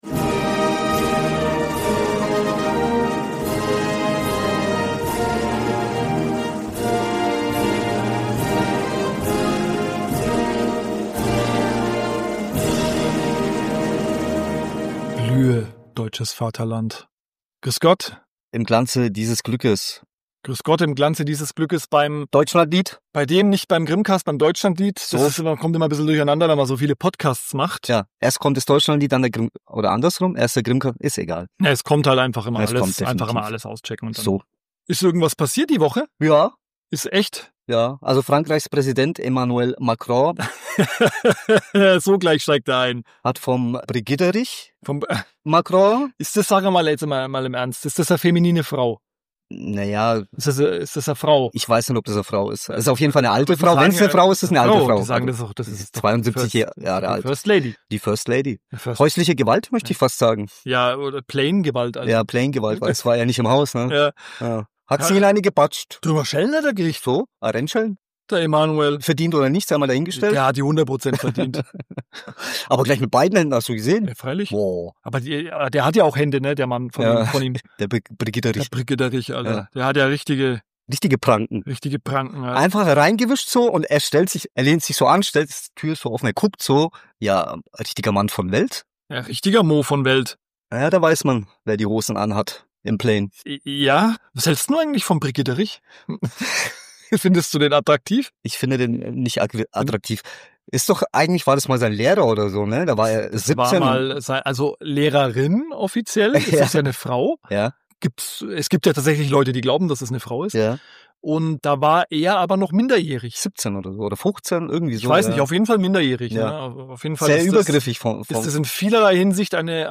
In gewohnt lockerer Stammtischmanier quatschen die grimmigen Brüder unter anderem über Elon Musks angeblichen Ketaminkonsum und abartige Ernährungstrends.